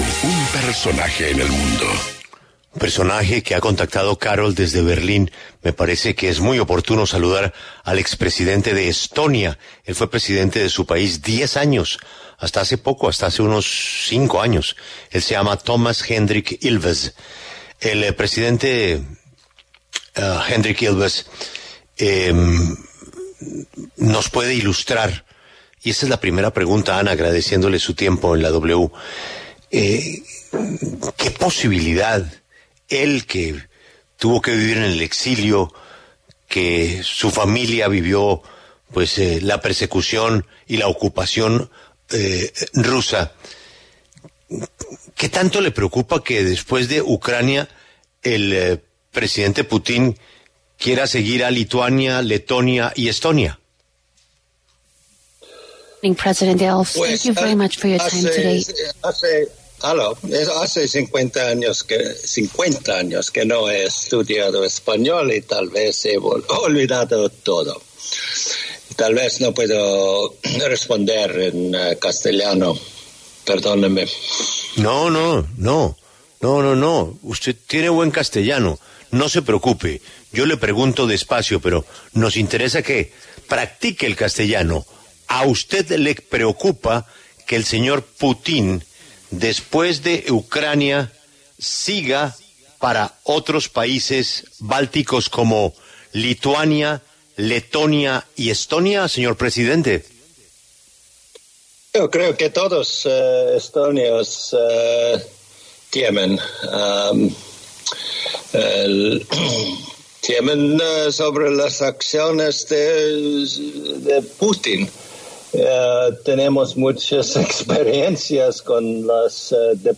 Toomas Hendrik Ilves, expresidente de Estonia, habló en La W sobre el anuncio del presidente de Ucrania de las intenciones de Putin de avanzar hacia los estados bálticos.